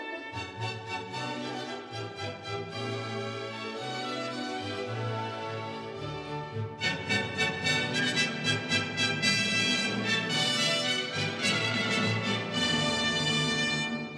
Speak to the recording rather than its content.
These are arranged in degrading quality.